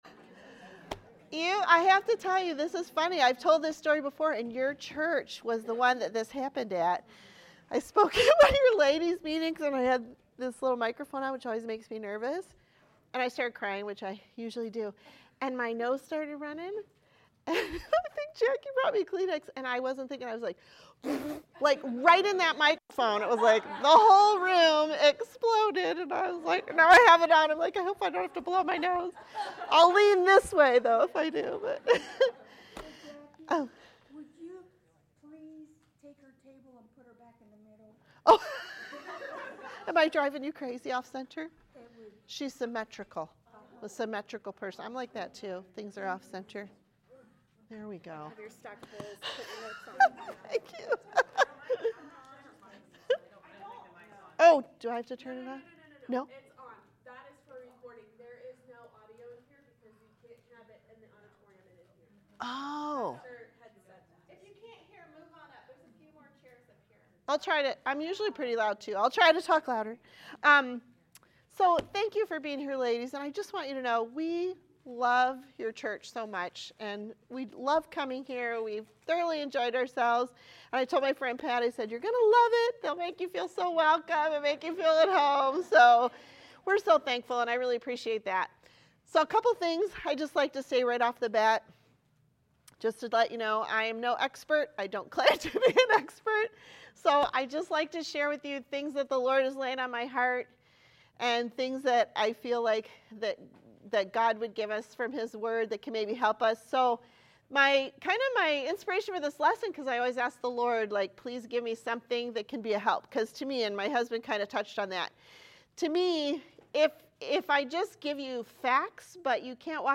Sermons | First Baptist Church
Marriage Retreat 2025